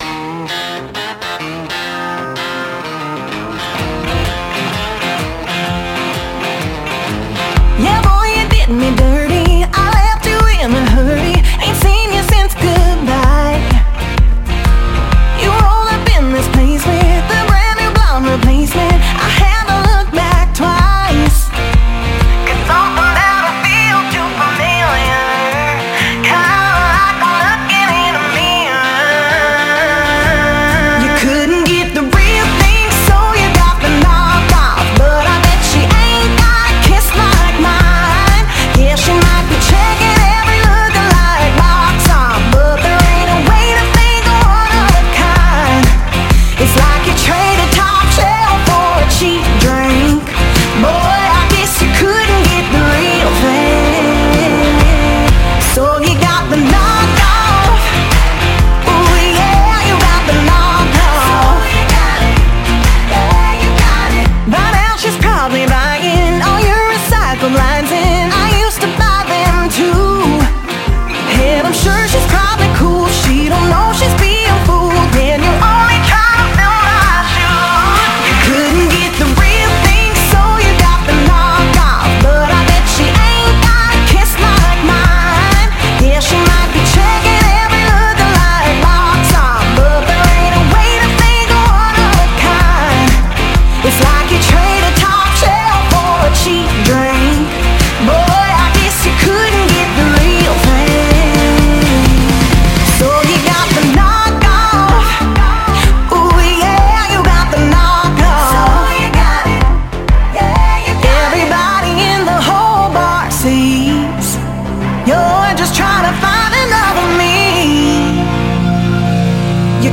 Dance Remix